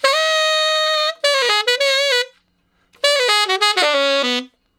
068 Ten Sax Straight (Ab) 27.wav